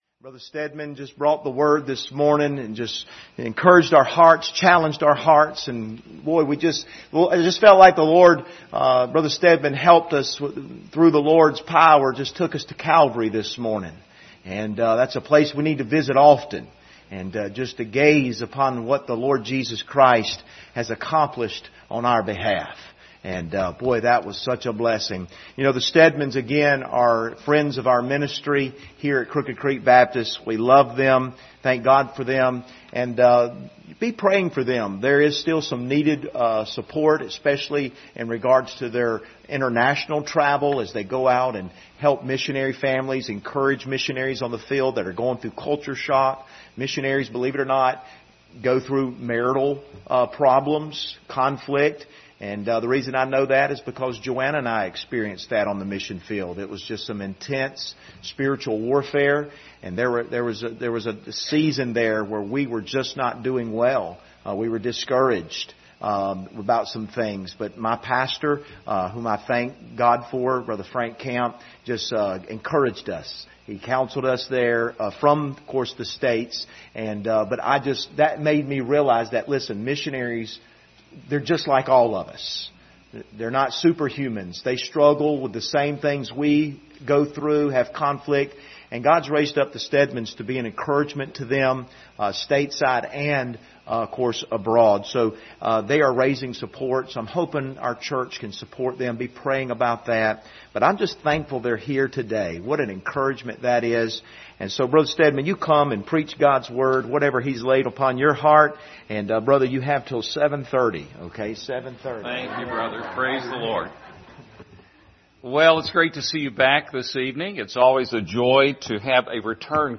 Passage: 2 Corinthians 9 Service Type: Sunday Evening